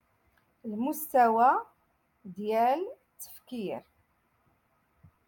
Moroccan Dialect-Rotation Six-Lesson Twenty Two